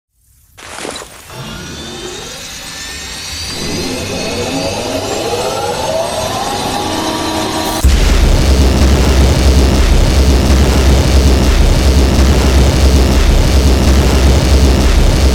large laser cameraman pantalla verde